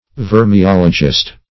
Search Result for " vermeologist" : The Collaborative International Dictionary of English v.0.48: Vermeologist \Ver`me*ol"o*gist\, n. One who treats of vermes, or worms; a helminthologist.